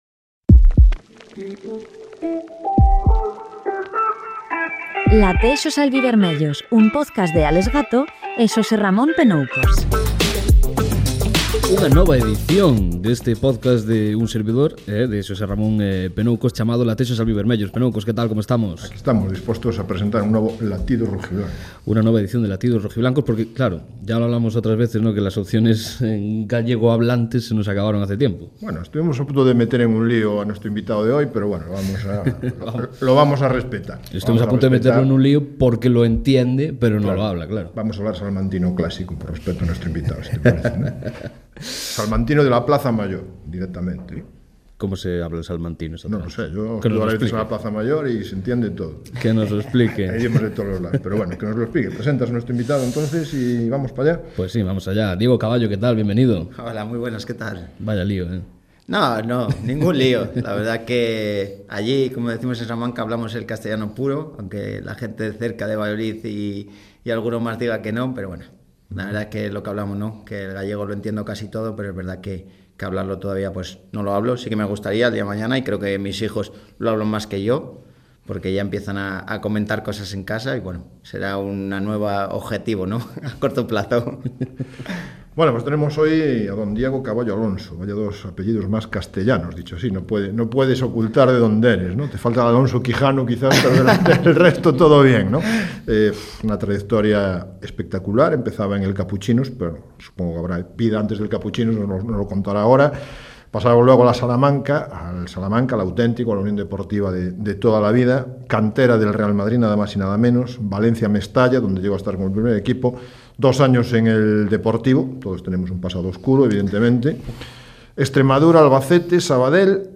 Recibimos a un dos veteráns desta tempada albivermella. Experiencia e fiabilidade no lateral esquerdo do Club Deportivo Lugo.